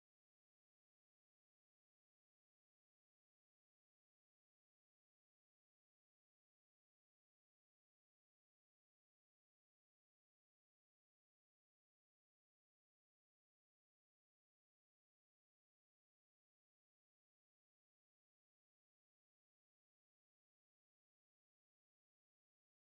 ממש כעת עשרות אלפים מפגינים בקרית הממשלה ותומכים ביועצת המשפטית לממשל